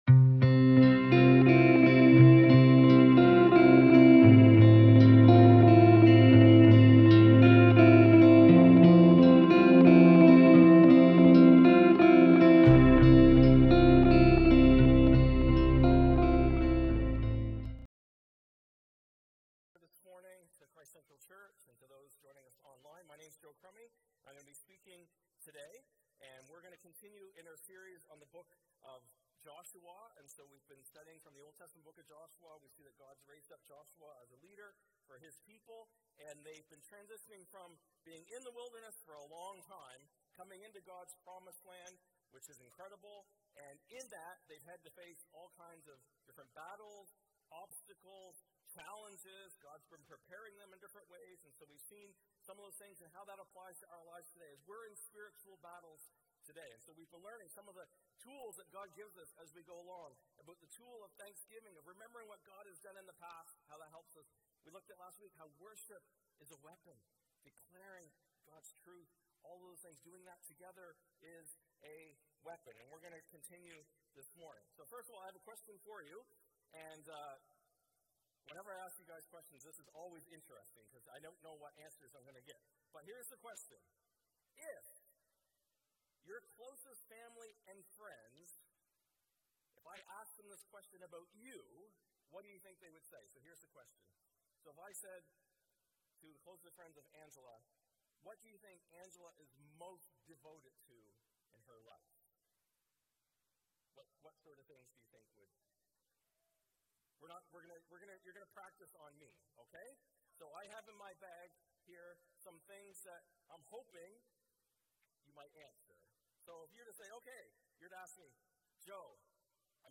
Sermons | Christ Central Church